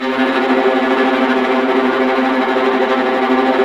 Index of /90_sSampleCDs/Roland - String Master Series/STR_Vlas Bow FX/STR_Vas Tremolo